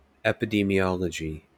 amer. IPA/ˌɛp.ɪˌdi.miˈɒl.ə.dʒi/ lub /ˌɛp.ɪˌdɛm.iˈɒl.ə.dʒi/